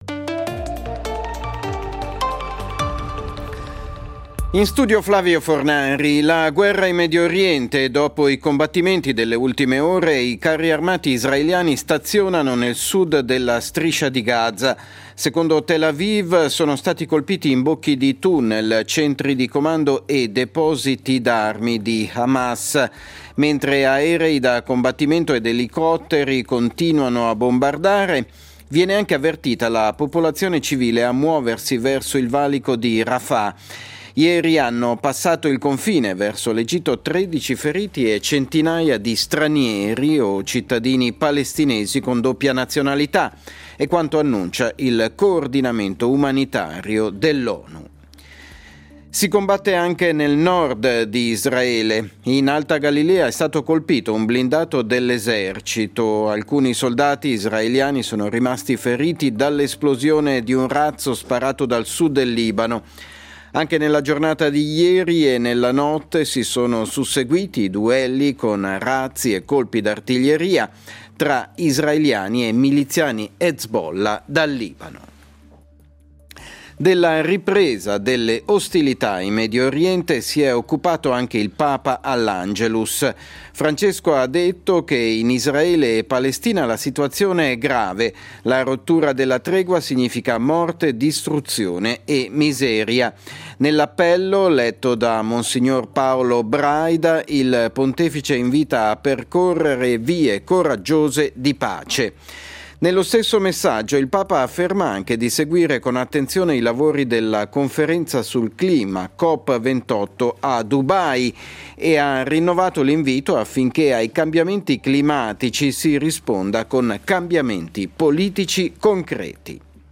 Notiziario delle 14:00 del 03.12.2023